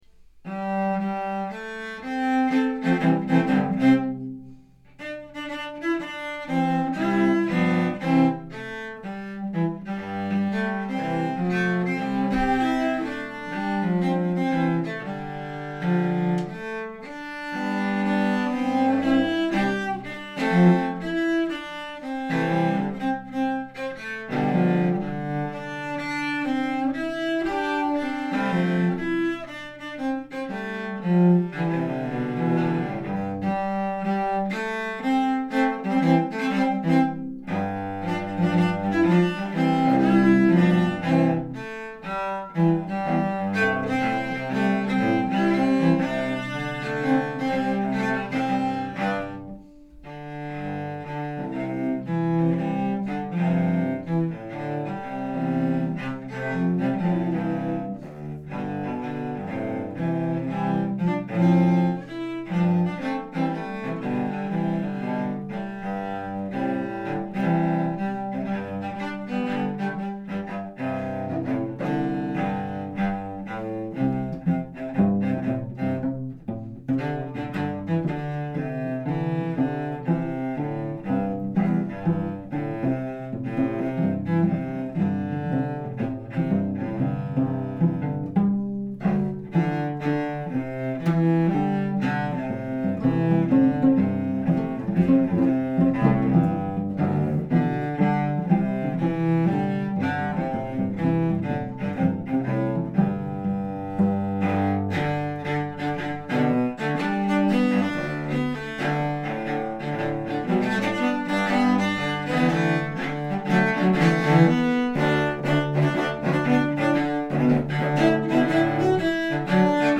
This arrangement for cello quartet applies a jazz style in contrast with the straight rhythms and traditional harmonies found in most contemporary settings. Six repetitions trade the melody across parts with varied syncopated accompaniments. Thick harmonies occasionally emerge from the linear part-focused writing. The free flowing style works best with a flexible quartet of four cellists.
Down-in-the-River-Cello-Quartet.mp3